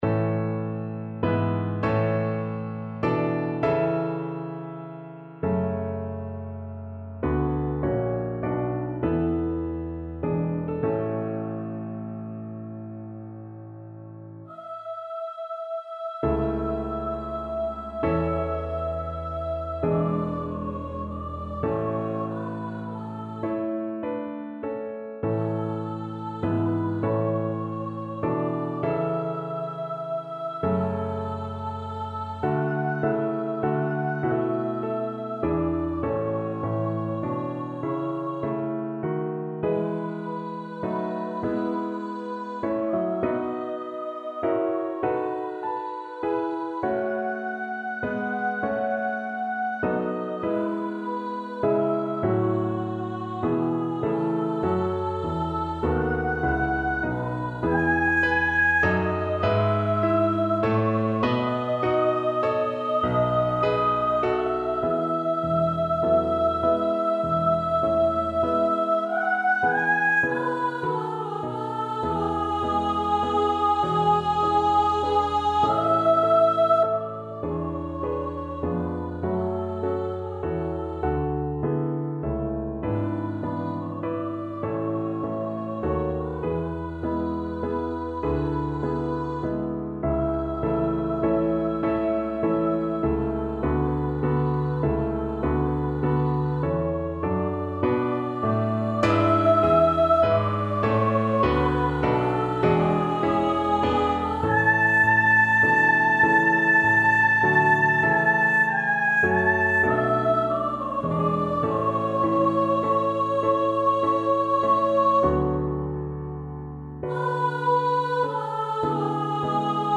Largo
F#5-A6
3/4 (View more 3/4 Music)
Classical (View more Classical Soprano Voice Music)